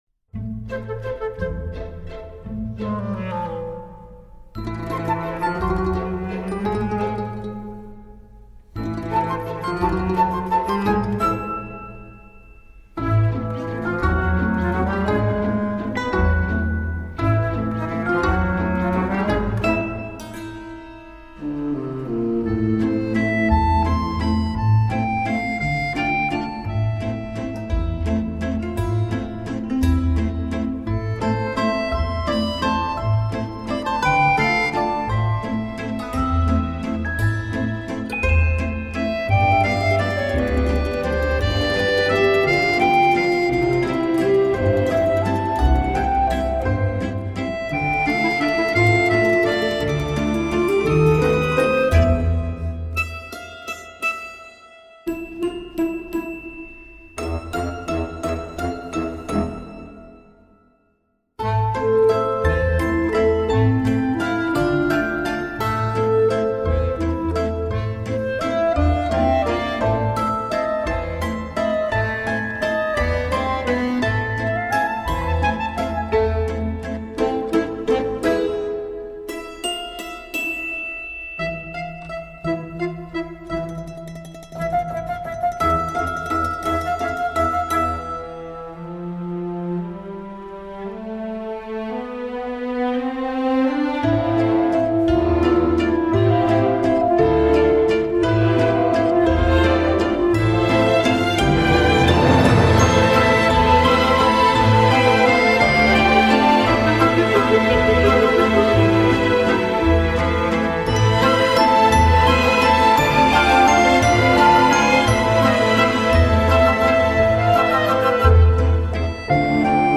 poignante musique dramatique
La force de l’épure, avec des pics expressifs.